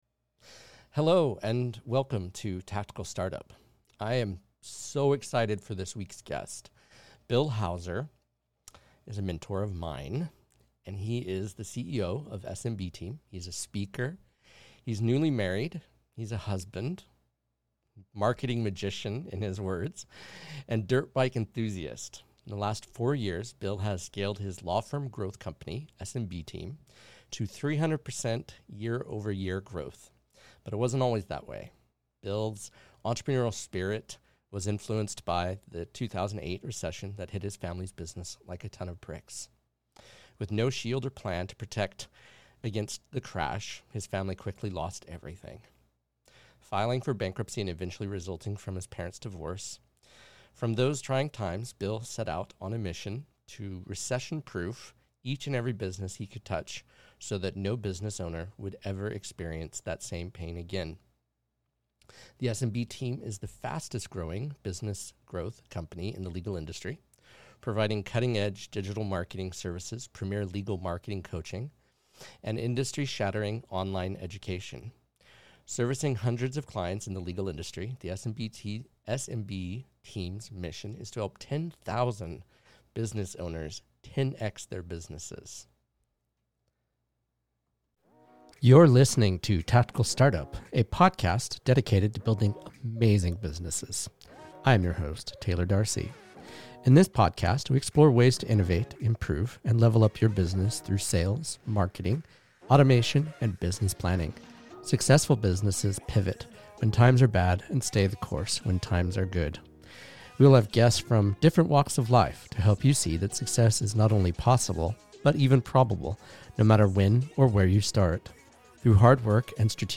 Shure SM7B Microphone
Rode Rodecaster Pro